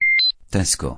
Dźwięki ostrzegawcze Tesco